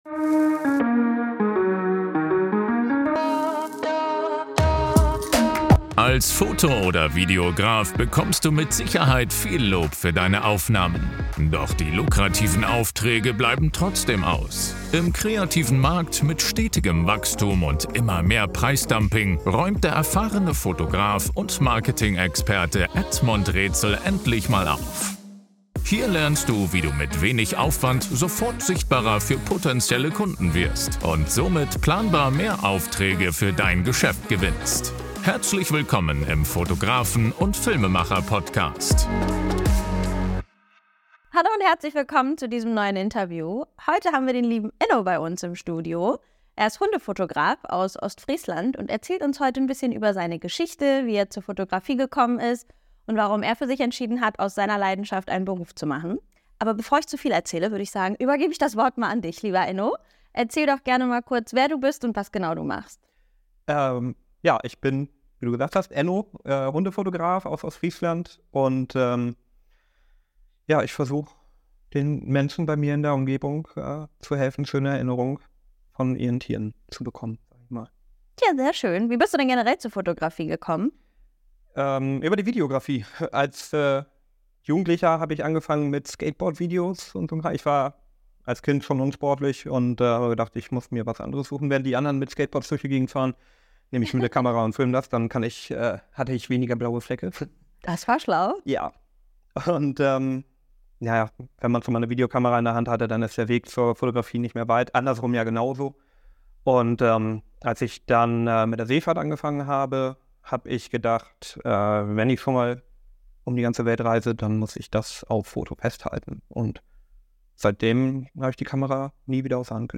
In diesem spannenden Interview enthüllen wir die Wahrheit über den Beruf des Hundefotografen.